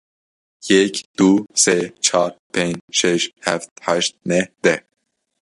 /seː/